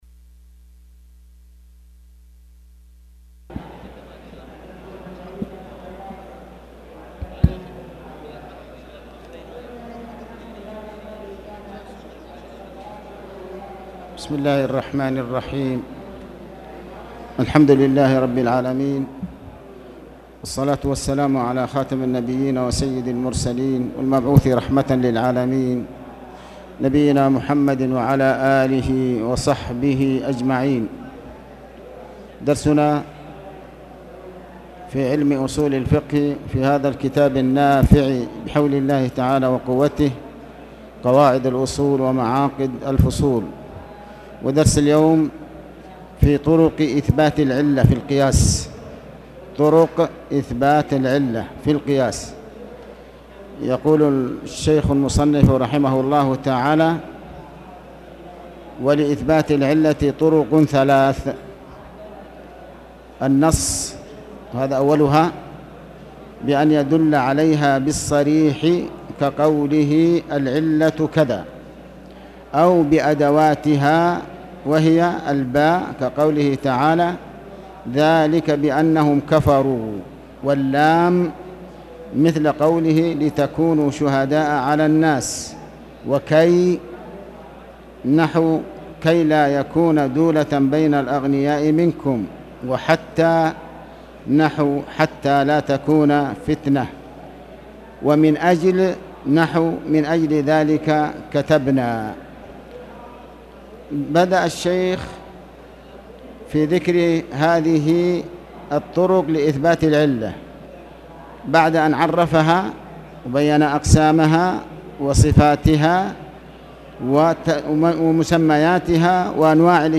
تاريخ النشر ١١ جمادى الأولى ١٤٣٨ هـ المكان: المسجد الحرام الشيخ: علي بن عباس الحكمي علي بن عباس الحكمي القياس